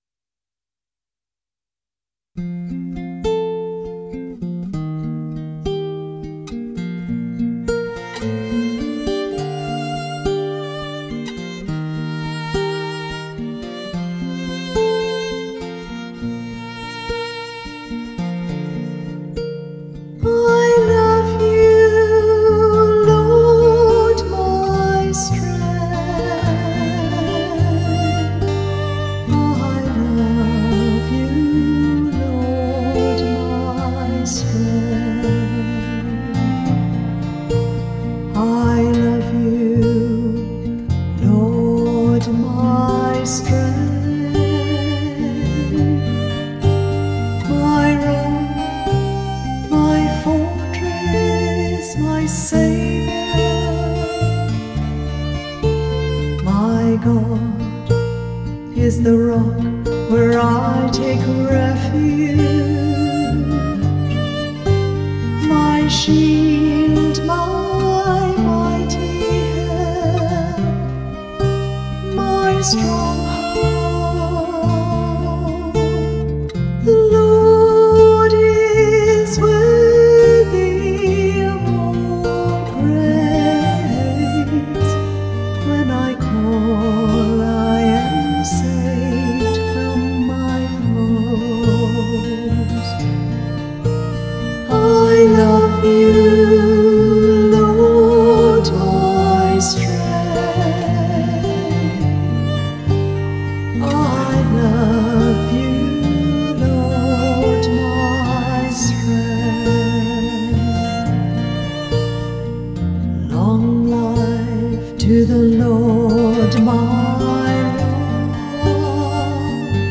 Music by the Choir of Our Lady of the Rosary RC Church, Verdun, St. John, Barbados.